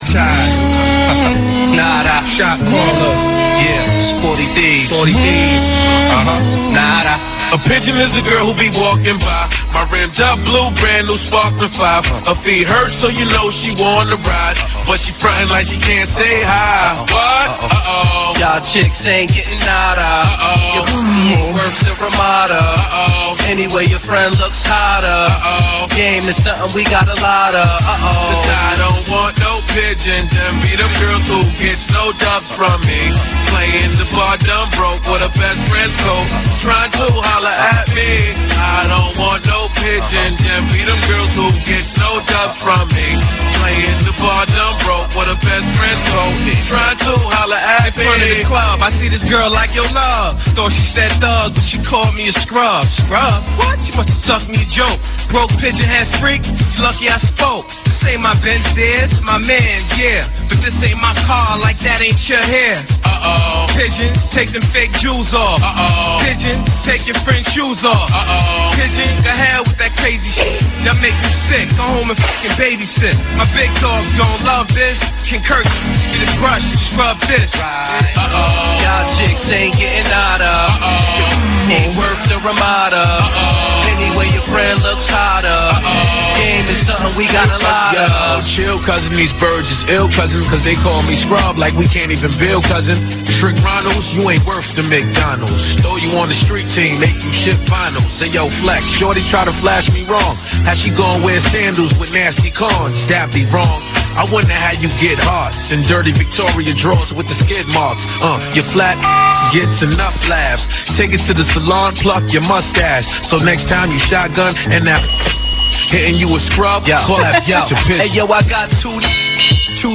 a spoof